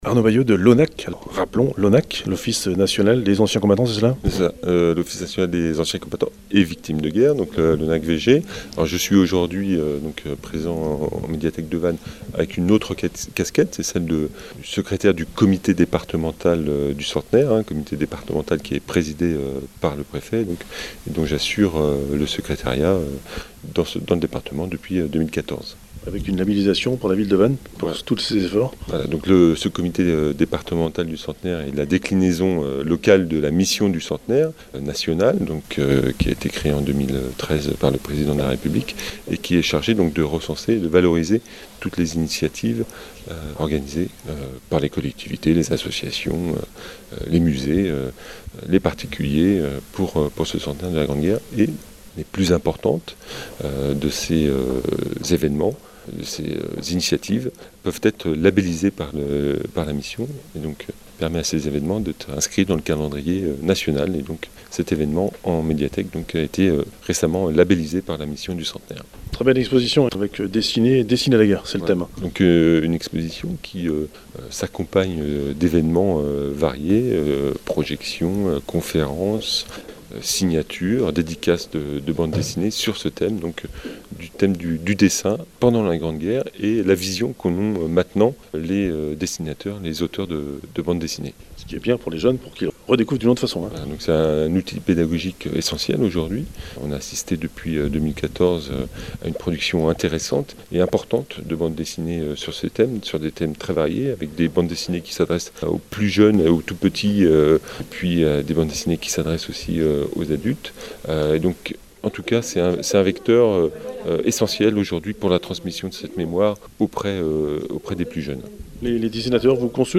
Interview Gabriel Sauvet – Maire Adjoint à la culture de Vannes